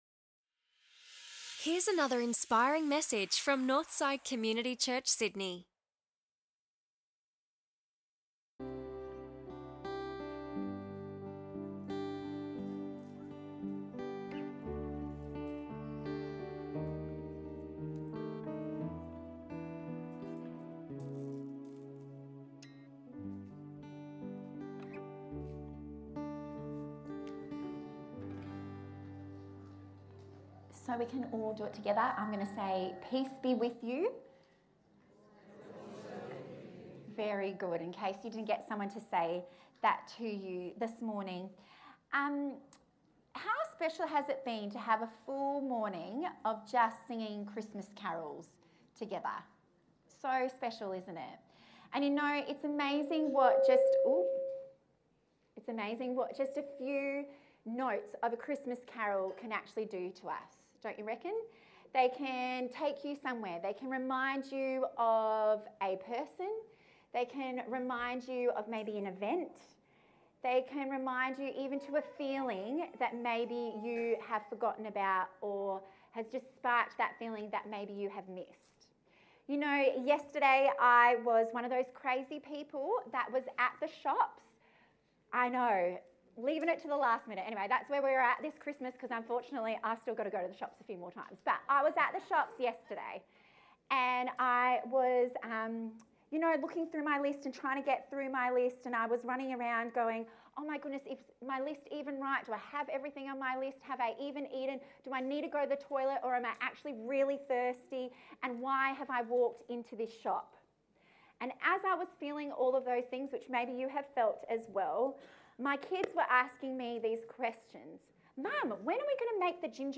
Guest Speaker